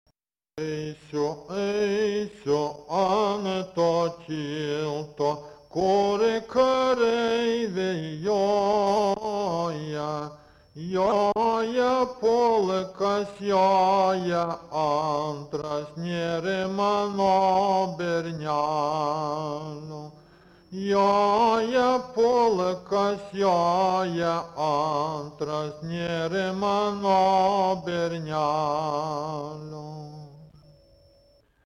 Subject daina
Atlikimo pubūdis vokalinis